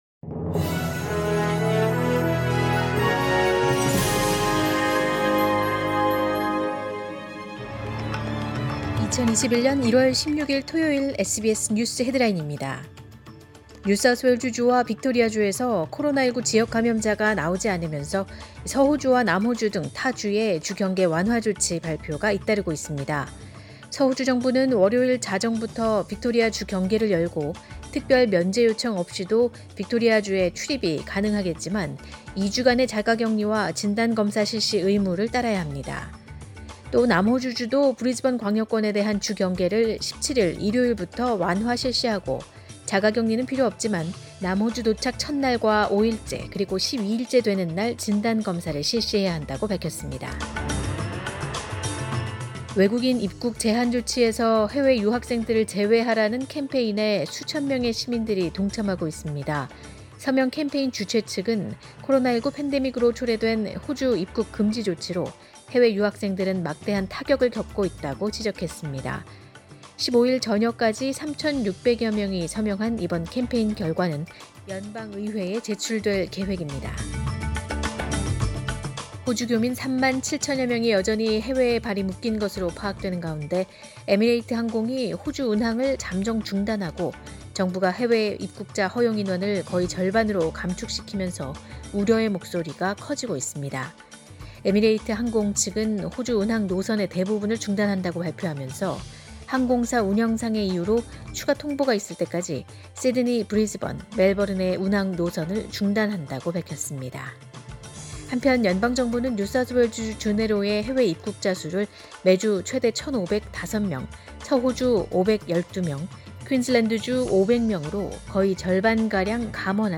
2021년 1월 16일 토요일 오전의 SBS 뉴스 헤드라인입니다.